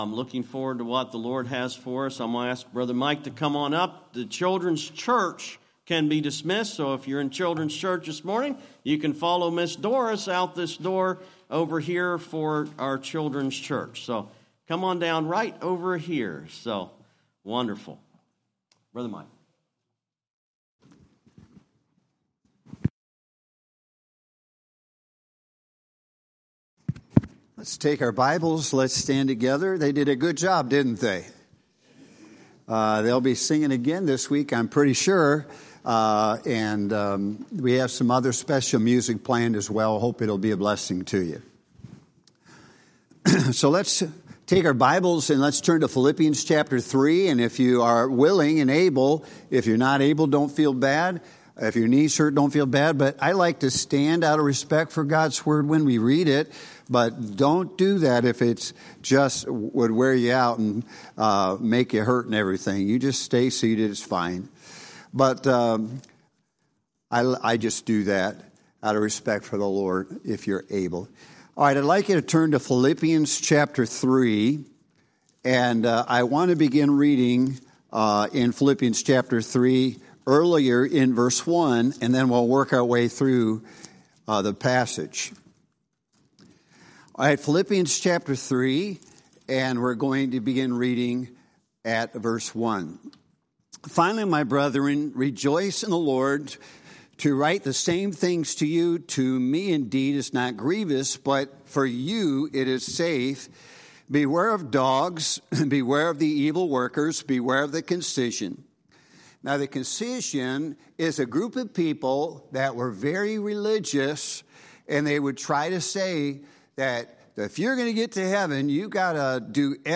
Sunday Morning Revival Service